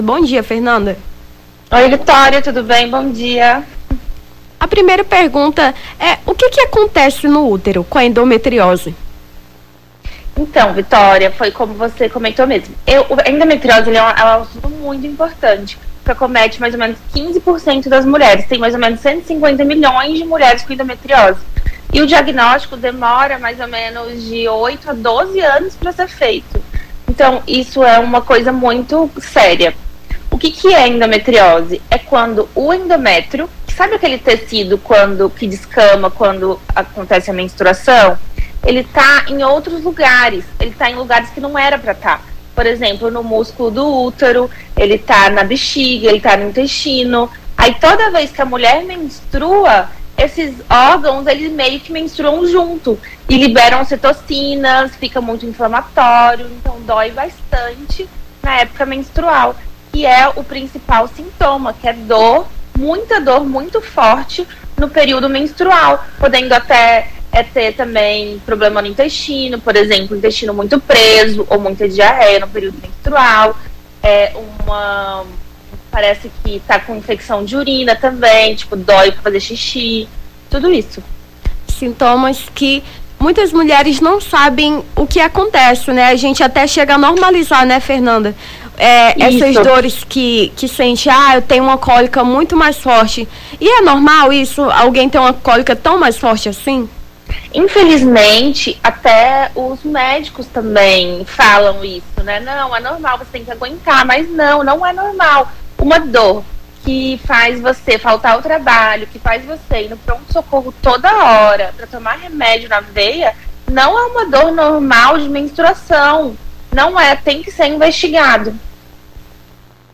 Nome do Artista - CENSURA - ENTREVISTA (ENDOMETRIOSE) 03-05-23.mp3